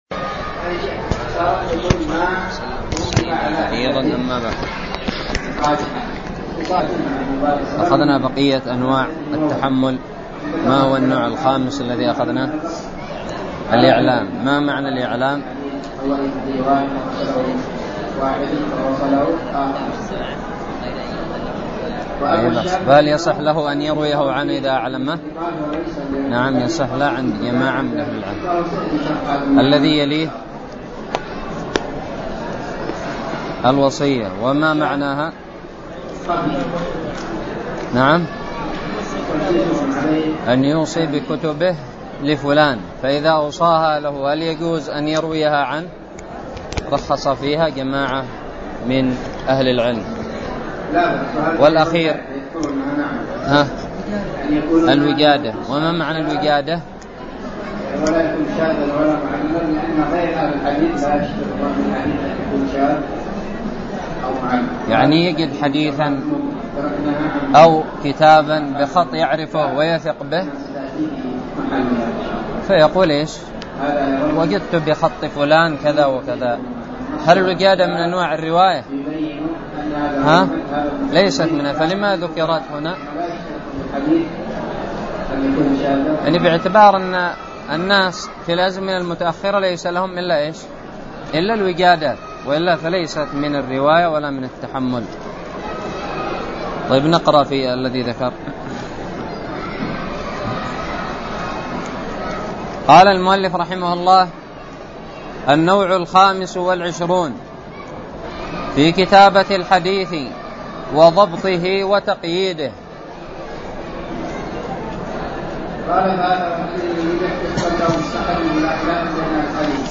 الدرس السادس والثلاثون من شرح كتاب الباعث الحثيث
ألقيت بدار الحديث السلفية للعلوم الشرعية بالضالع